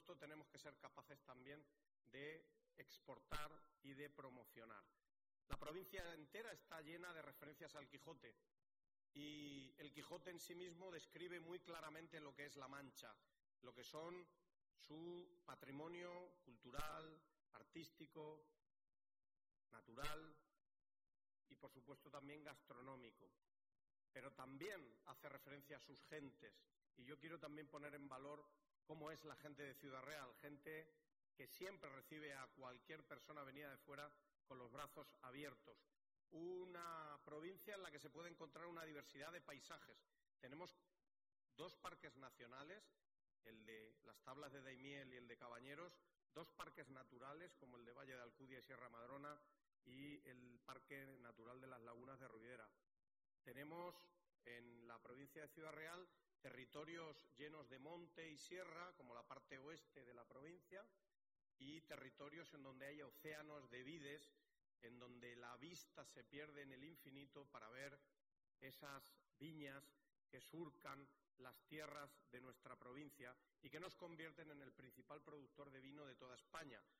El presidente de la Diputación de Ciudad Real, Miguel Ángel Valverde, acompañado por la vicepresidenta de Impulso Cultural y Turístico, María Jesús Pelayo, ha presentado en el Palacio de Congresos de Córdoba la iniciativa “Sabor Quijote”, un evento que busca posicionar la provincia como un referente turístico, cultural y gastronómico dentro y fuera de sus fronteras.